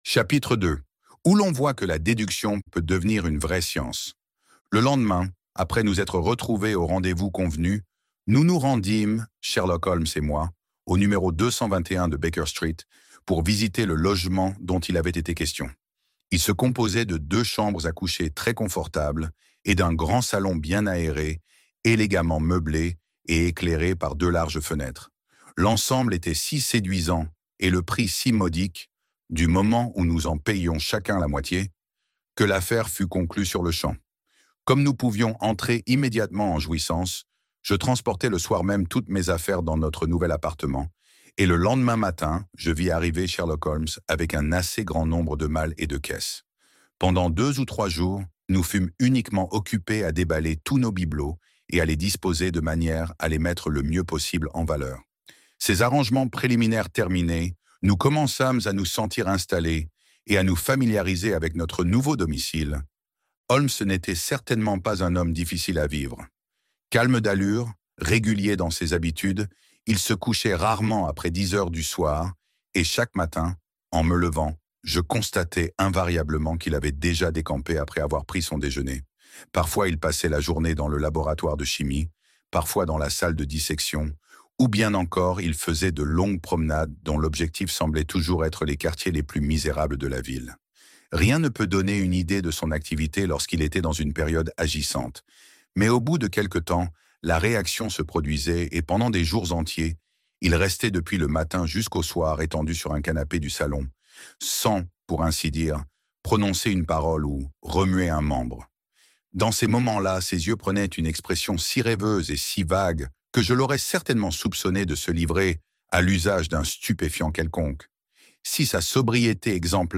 Une étude en rouge. Sherlock Holmes - Livre Audio